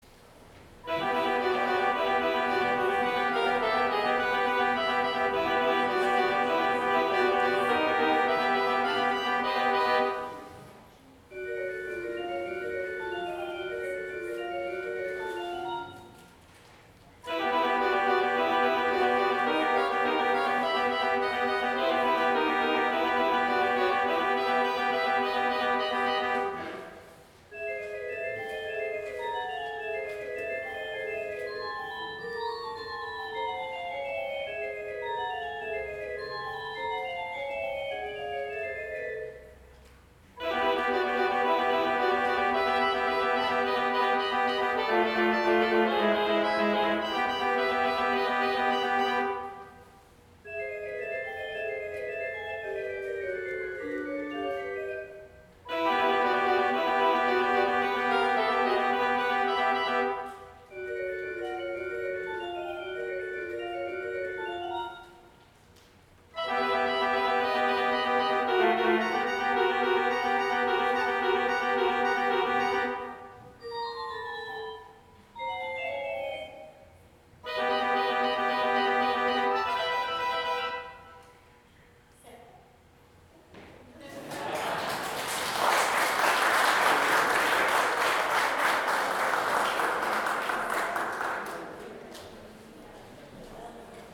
Organ – “Dialogue of the Angels” | Young United Church